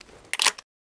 Divergent / mods / Spas-12 Reanimation / gamedata / sounds / weapons / spas / load1.ogg